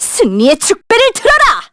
Miruru_L-Vox_Victory_kr.wav